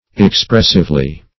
-- Ex*press"ive*ly,adv.